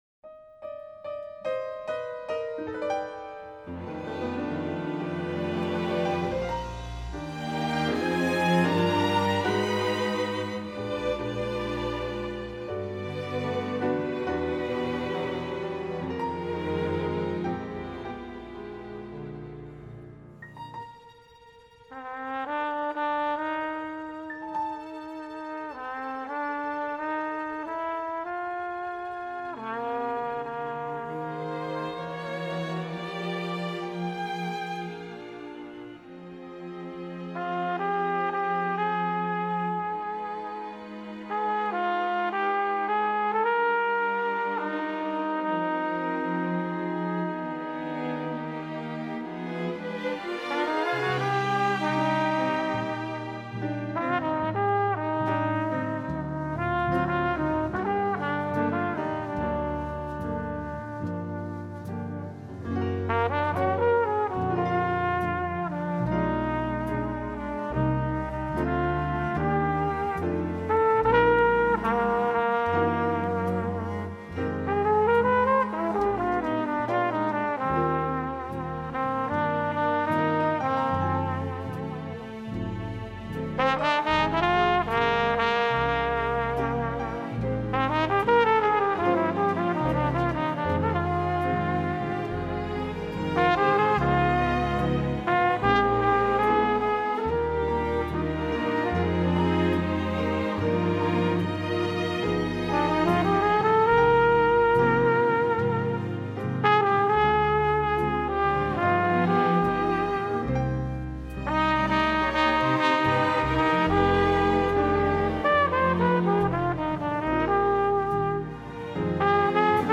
featuring Flugelhorn Solo with Strings
Voicing: String Orchestra W